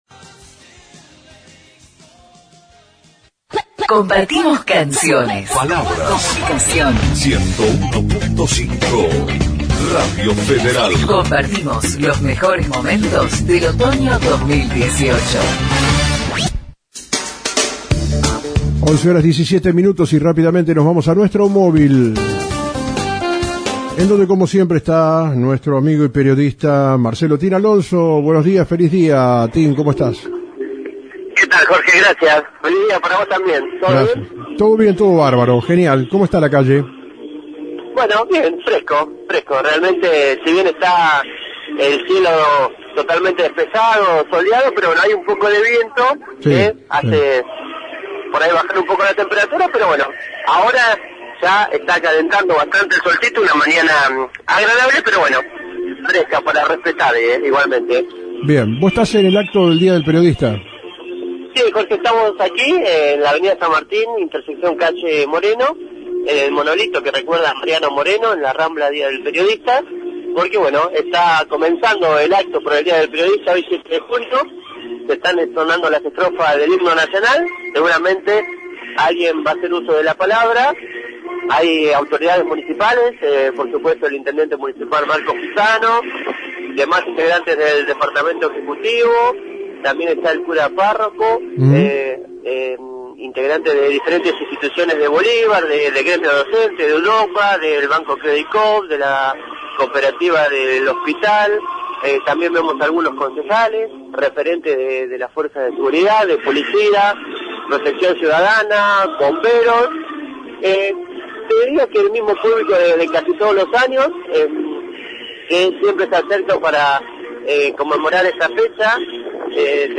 Acto del Día del Periodista :: Radio Federal Bolívar
Palabras Alusivas